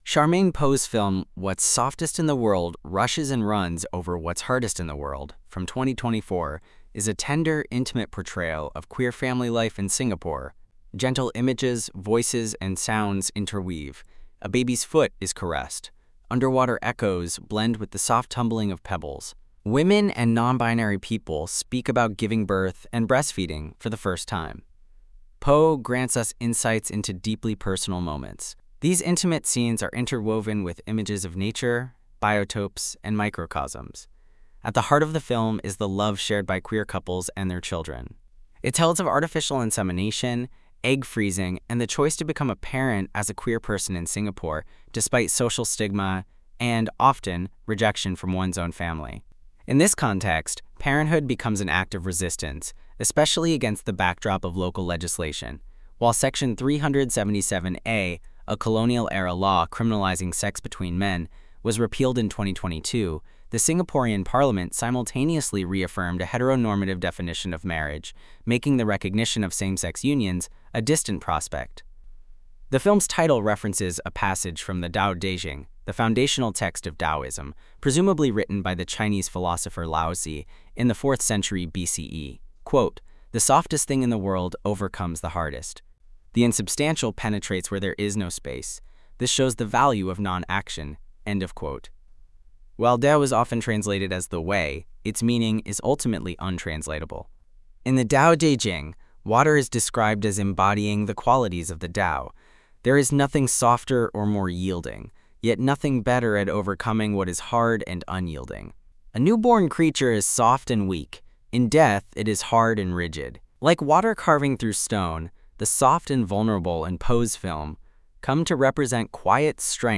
Note: The audio transcription is voiced by an AI.